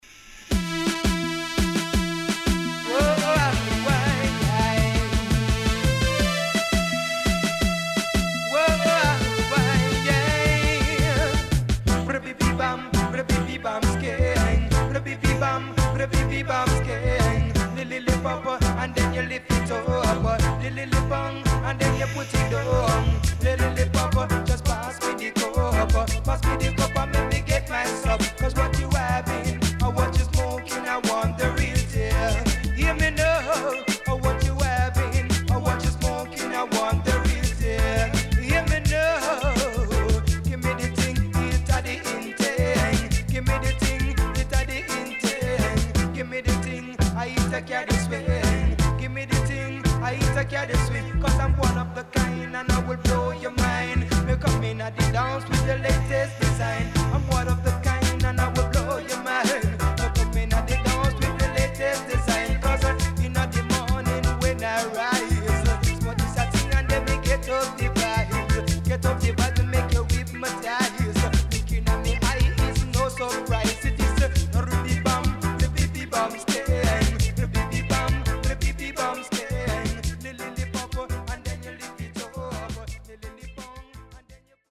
86年Killer Digital Dancehall Master Piece